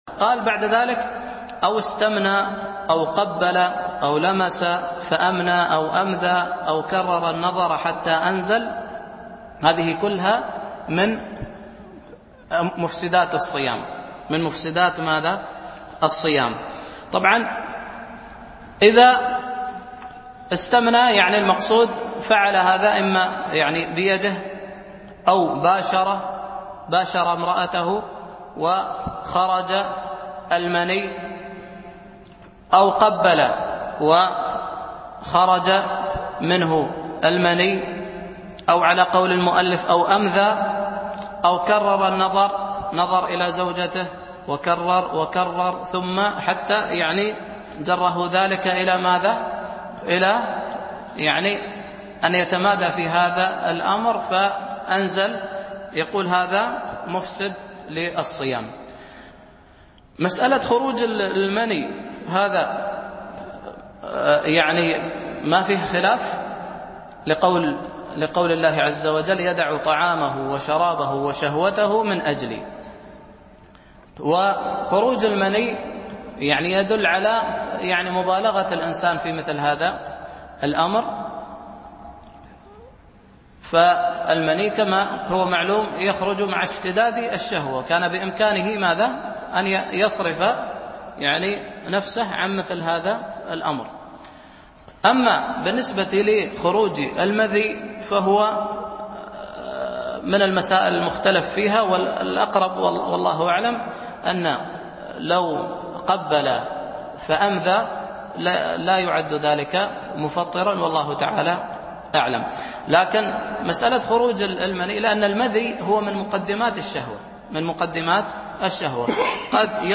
الألبوم: دروس مسجد عائشة (برعاية مركز رياض الصالحين ـ بدبي) المدة: 2:48 دقائق (682.19 ك.بايت) التنسيق: MP3 Mono 22kHz 32Kbps (VBR)